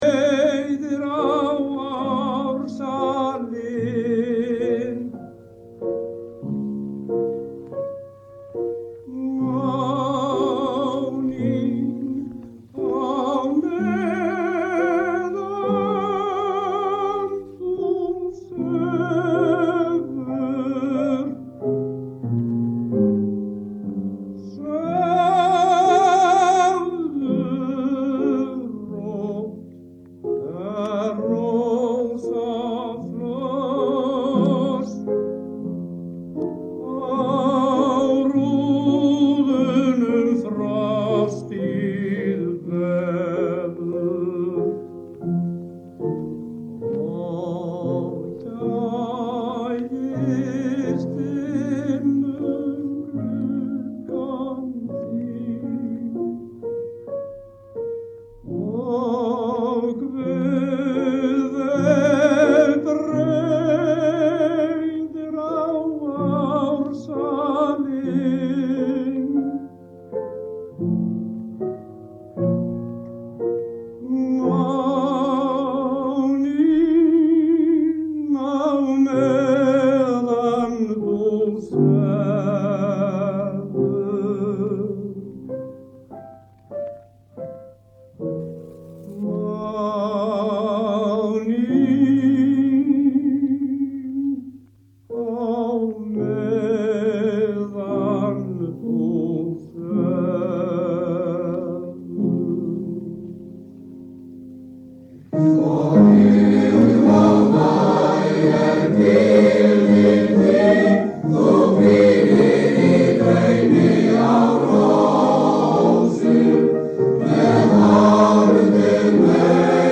tekið upp á æfingu